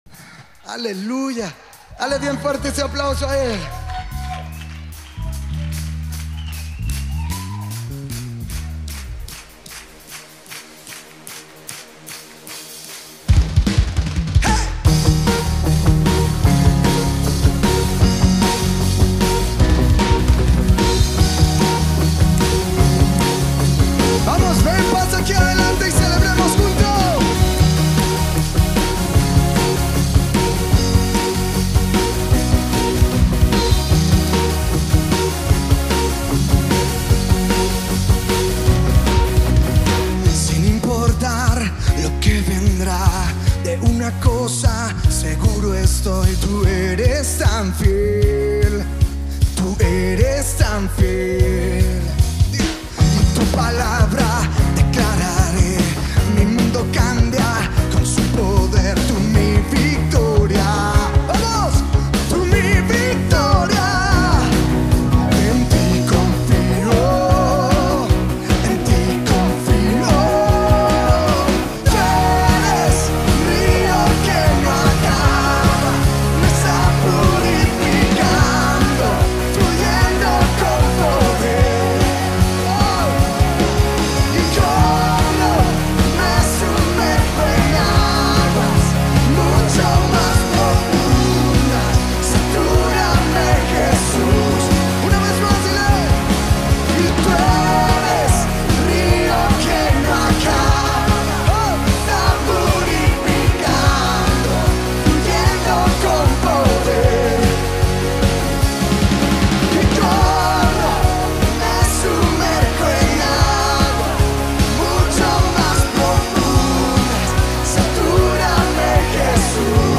Río (En Vivo).mp3